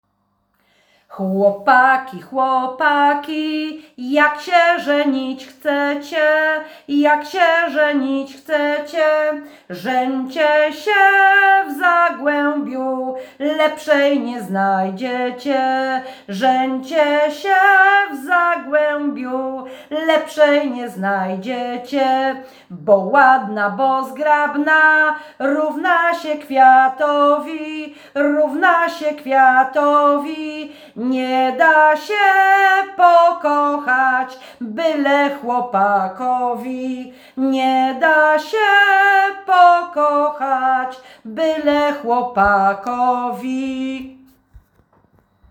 Chłopaki, chłopaki – Żeńska Kapela Ludowa Zagłębianki
Nagranie współczesne